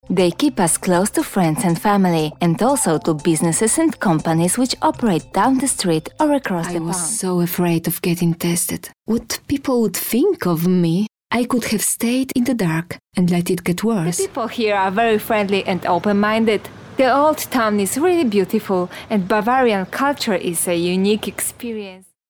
Polnisch E-Learning